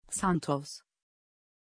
Pronunția numelui Xanthos
pronunciation-xanthos-tr.mp3